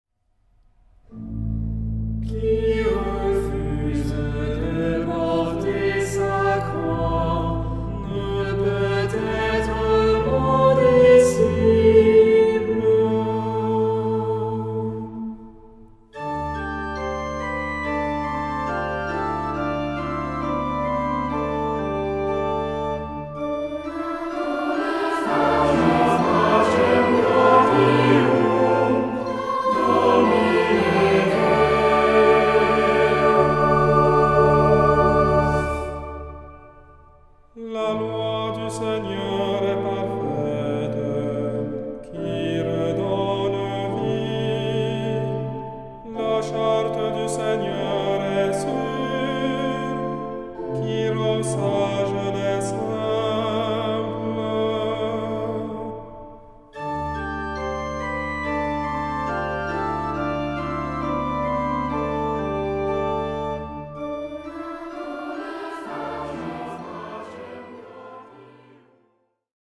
Genre-Style-Form: troparium ; Psalmody
Mood of the piece: collected
Type of Choir: SATB  (4 mixed voices )
Instruments: Organ (1)
Tonality: A minor ; D dorian